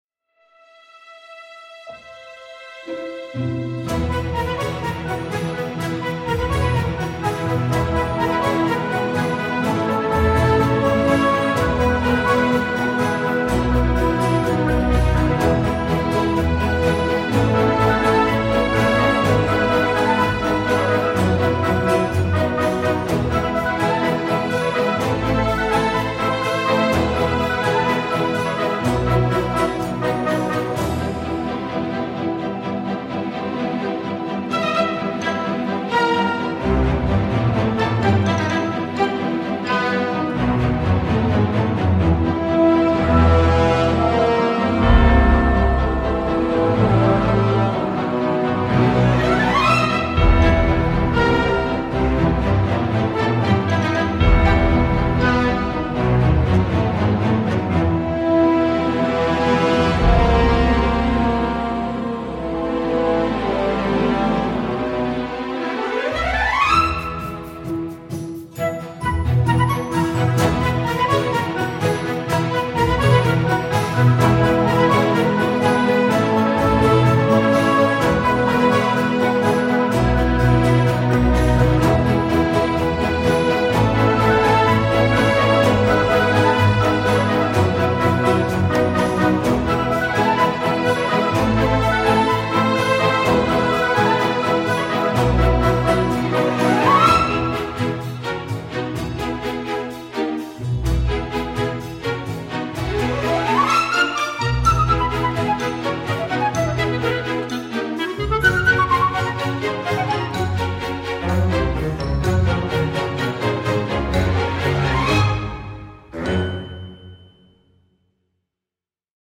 une partition symphonique qui regarde en arrière
une partition d’aventure, espiègle et nocturne